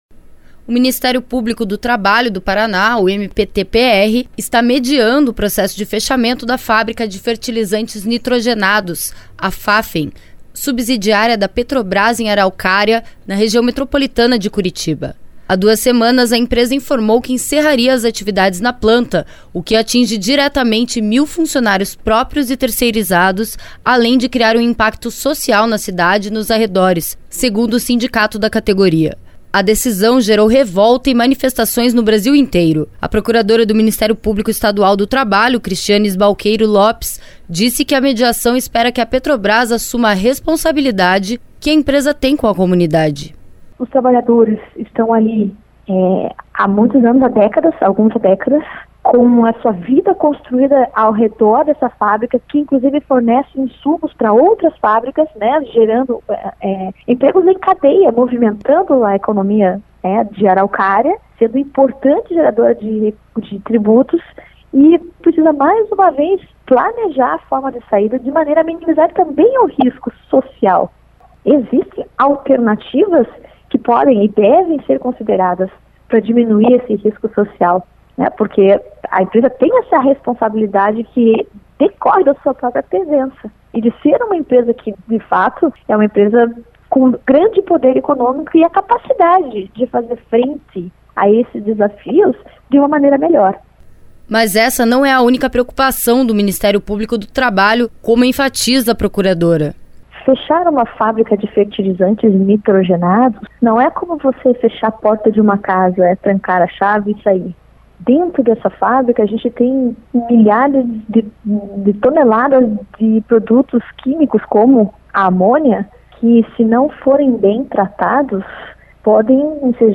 Mas essa não é a única preocupação do Ministério Público do Trabalho, como enfatiza a procuradora.